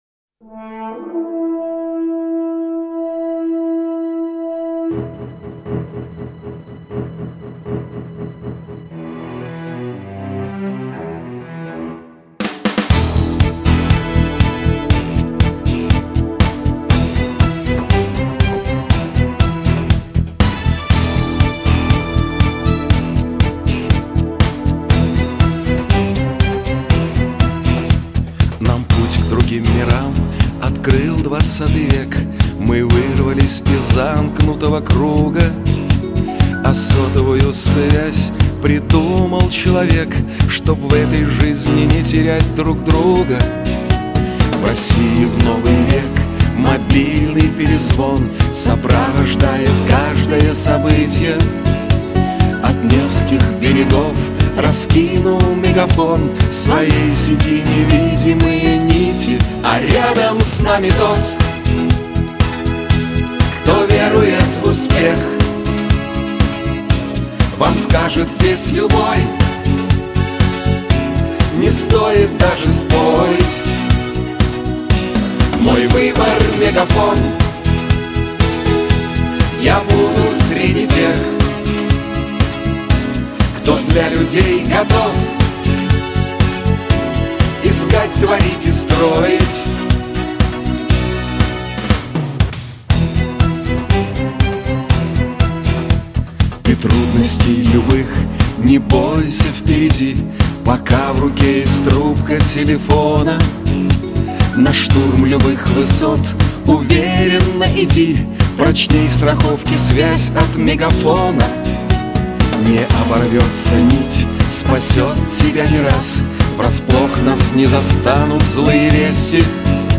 с текстом